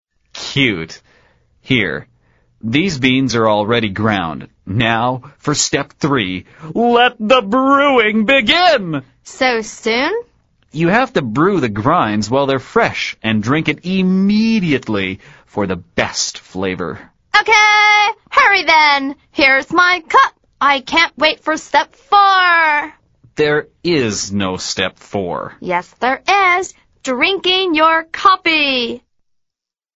美语会话实录第99期(MP3+文本):The best flavor